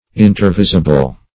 Search Result for " intervisible" : The Collaborative International Dictionary of English v.0.48: Intervisible \In`ter*vis"i*ble\, a. (Surv.) Mutually visible, or in sight, the one from the other, as stations.